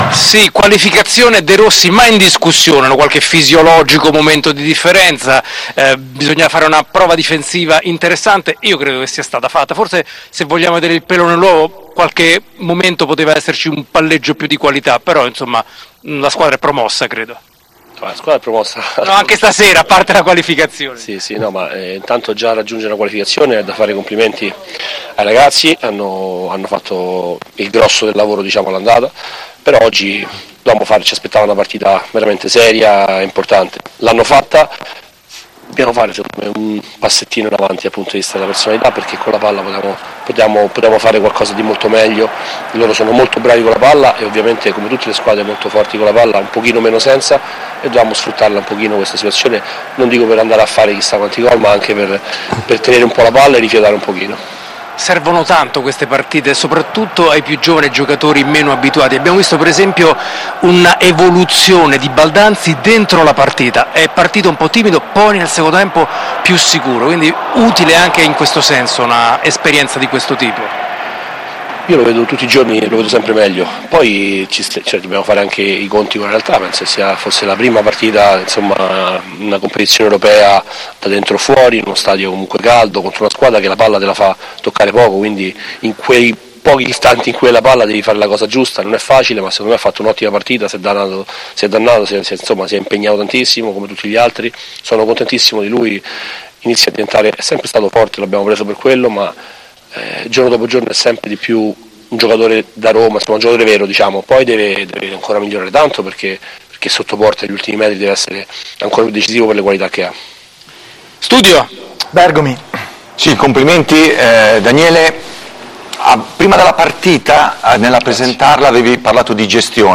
Al termine del match contro il Brighton, l’allenatore della Roma, Daniele De Rossi, ha rilasciato alcune dichiarazioni al riguardo: